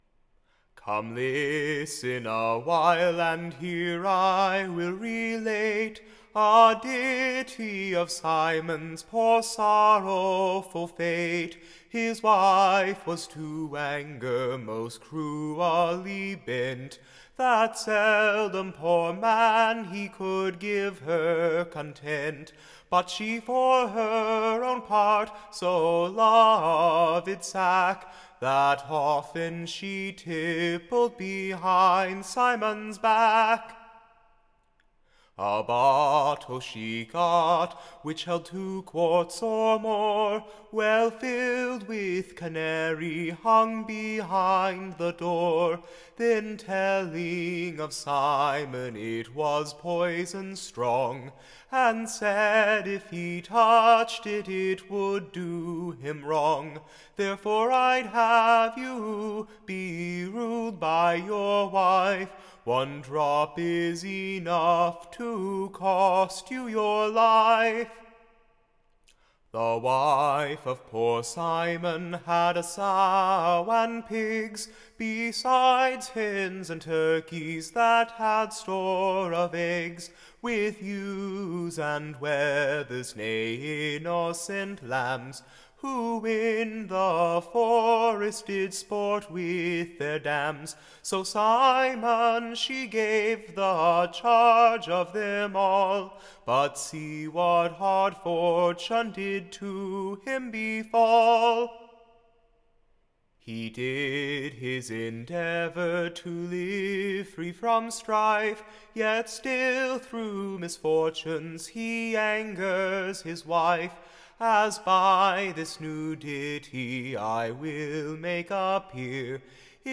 Recording Information Ballad Title Simple SIMON's Misfortunes, / And his Wife MARGERY'S Cruelty; / Who Poisoned Him with a Bottle of Sack.